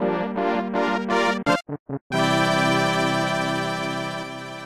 Yoshi goal music